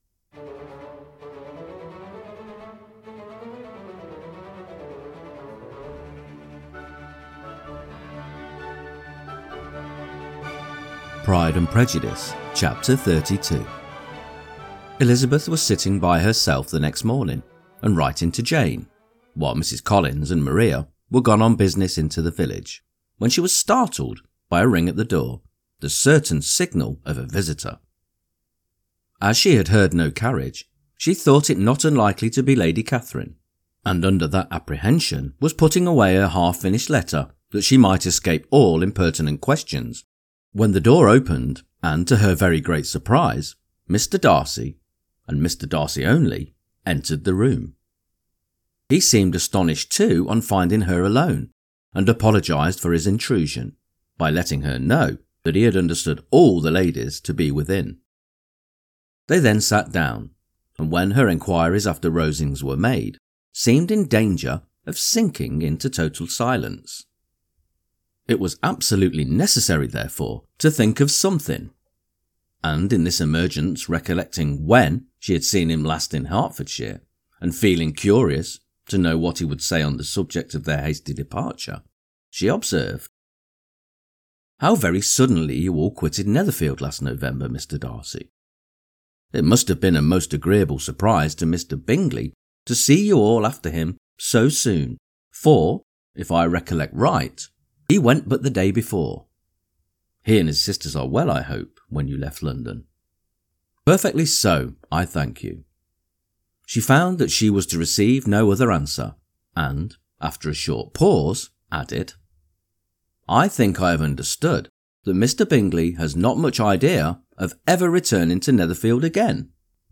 Pride and Prejudice – Jane Austen Chapter 32 Narrated - Dynamic Daydreaming
The more I narrate each chapter, the feistier Miss Elizabeth Bennet becomes!